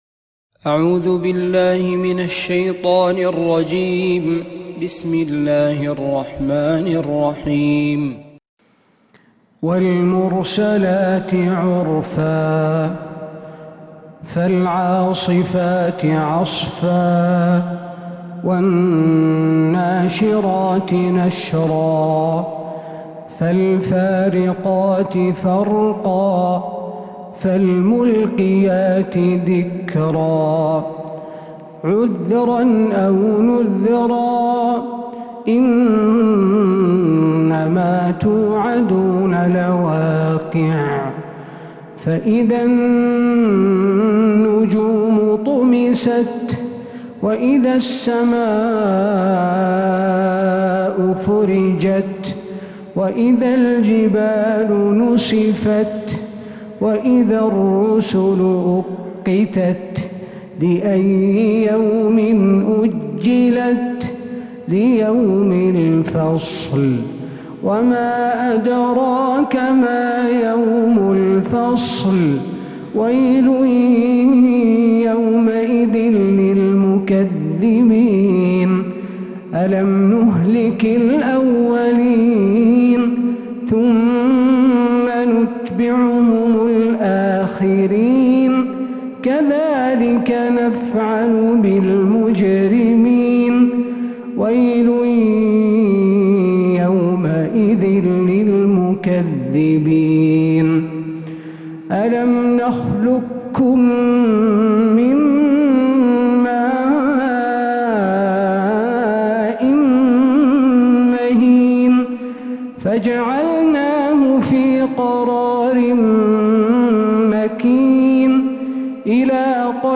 سورة المرسلات كاملة من فجريات الحرم النبوي